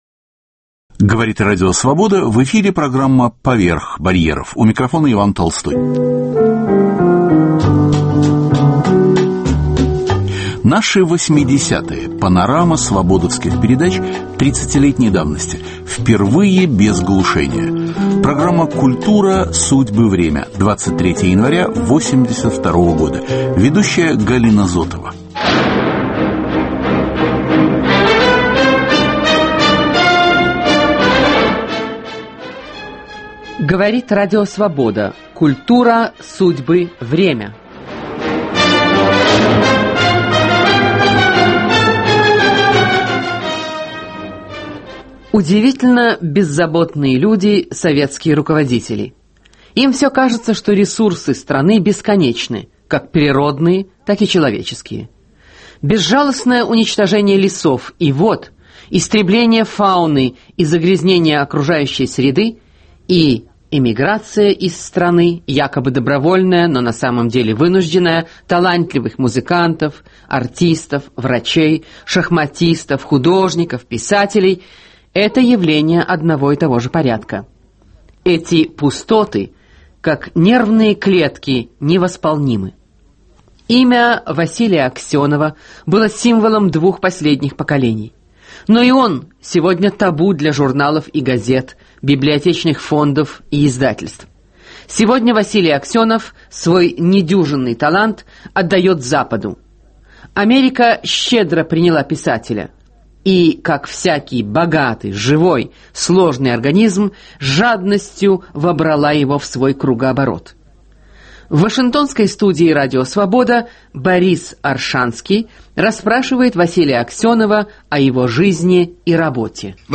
Наши 80-е без глушения. Панорама передач 30-летней давности. Выступления Василия Аксенова, Сергея Довлатова, Фридриха Горенштейна, Владимира Максимова, Натальи Горбаневской и других.